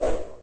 fly.ogg